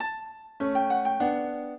piano
minuet13-4.wav